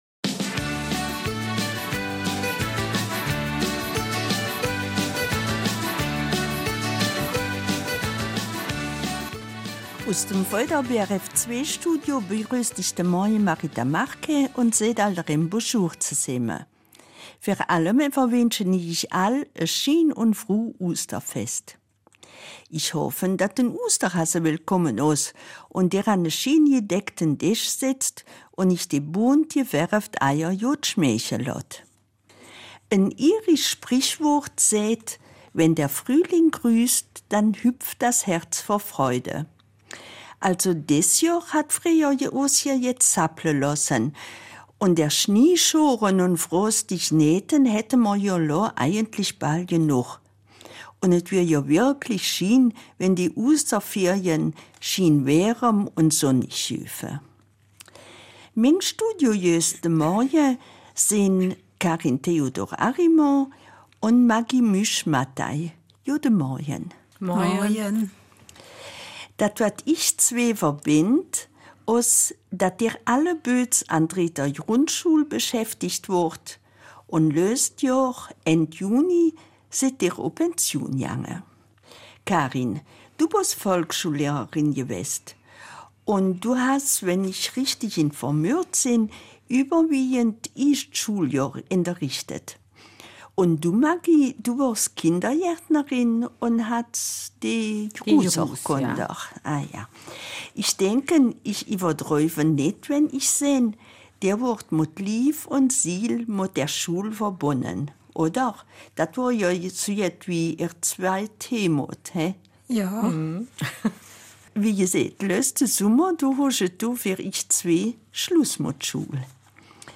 Eifeler Mundart: Pensionierung - Schule ade